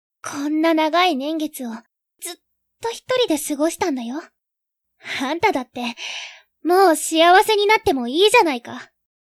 性別：女